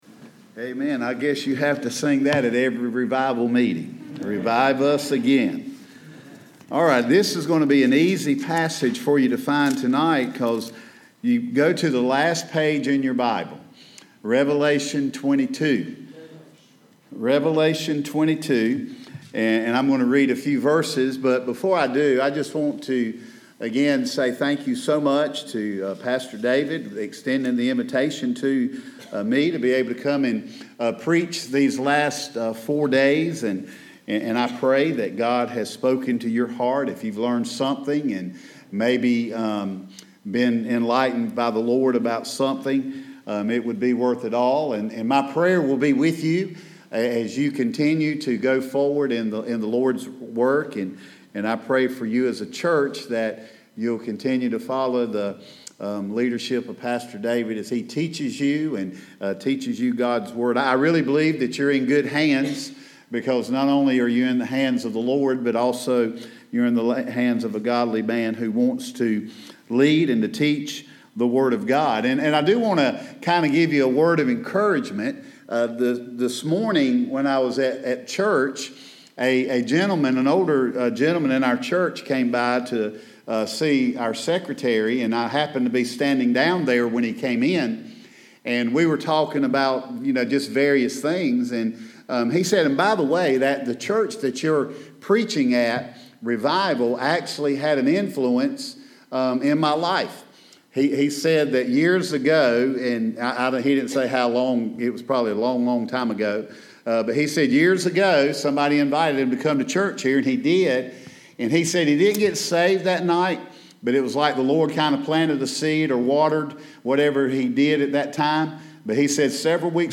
Guest Preaching | Lowrys First Baptist Church
Sunday Morning Worship (8/18/2024) – Psalm 60 “Our Triumphant God”